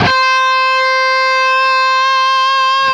LEAD C 4 CUT.wav